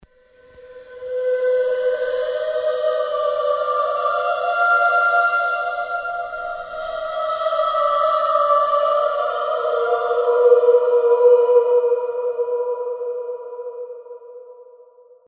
描述：高环境类型的歌剧人声，自然音调，无音调转换，八小节，循环播放。
Tag: 126 bpm Ambient Loops Vocal Loops 2.56 MB wav Key : Unknown